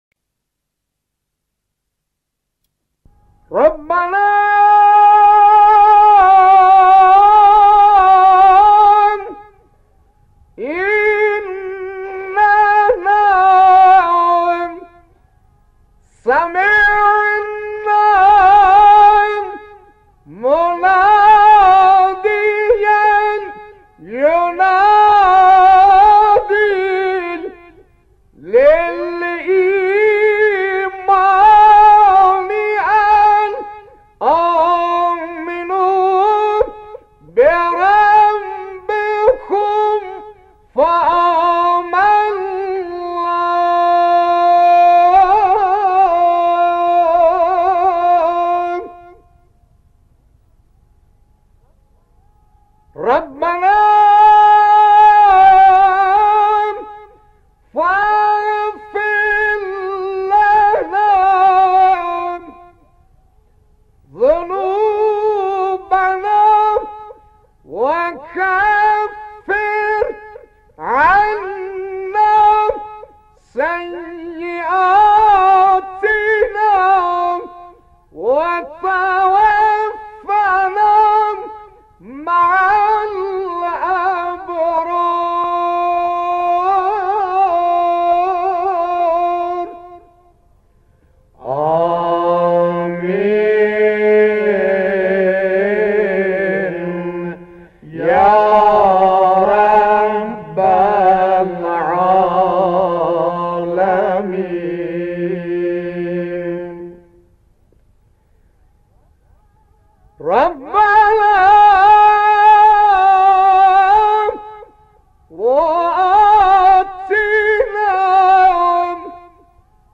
صوت/ دعای ربنا با صدای موذن‌زاده‌ اردبیلی
در این فایل‌ صوتی، دعای «ربنا» را با صدای موذن زاده اردبیلی می‌شنوید.